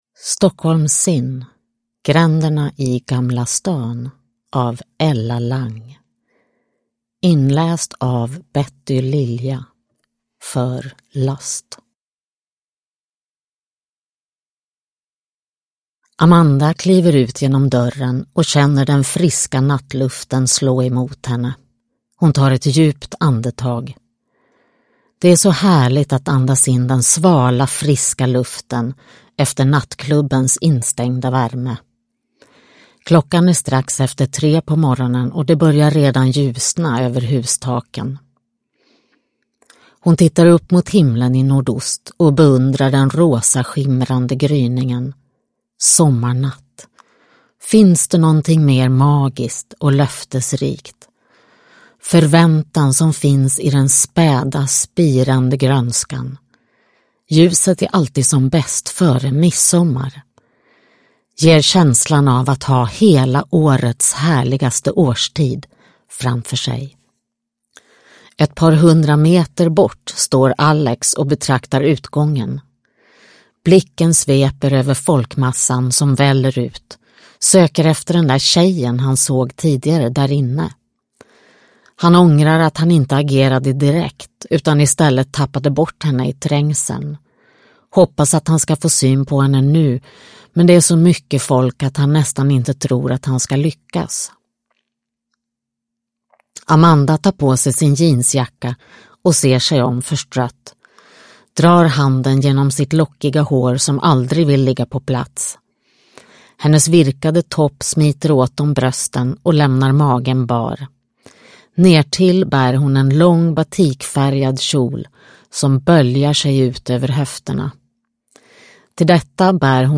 Stockholm Sin: Gränderna i Gamla Stan (ljudbok) av Ella Lang